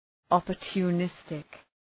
Προφορά
{,ɒpərtu:’nıstık}